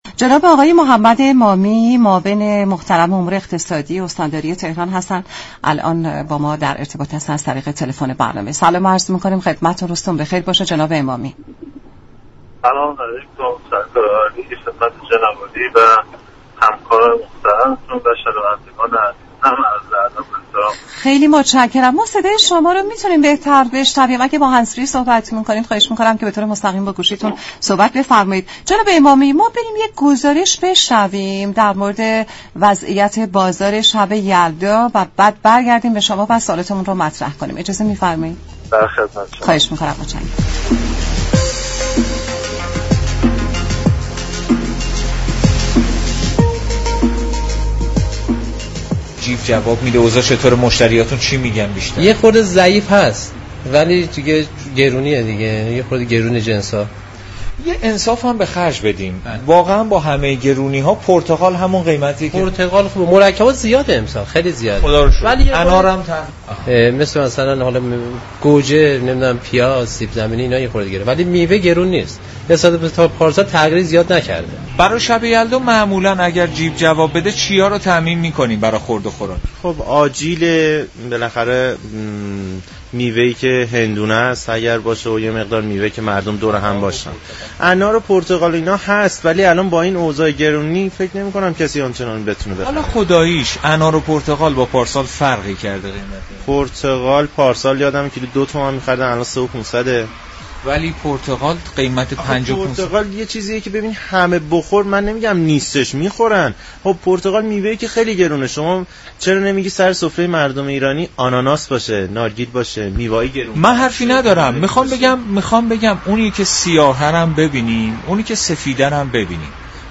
به گزارش شبكه رادیویی ایران، محمد امامی امین معاون هماهنگی امور اقتصادی استاندار تهران در گفت و گو با برنامه «نمودار» درباره وضعیت بازار شب یلدا گفت: دولت در بازار و میادین میوه و تره بار، كالاهای مورد نیاز مردم نظیر گوشت، مرغ و میوه را به وفور عرضه و در اختیار قرار داده است.